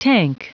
Prononciation du mot tank en anglais (fichier audio)
Prononciation du mot : tank